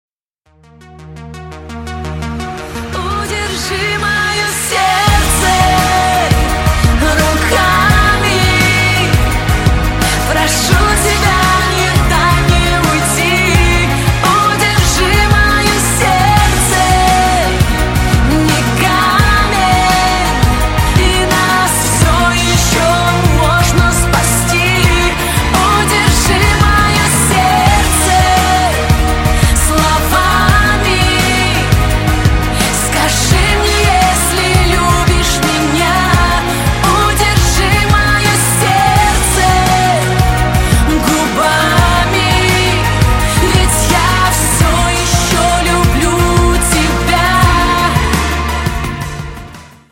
• Качество: 128, Stereo
поп
женский вокал
сильные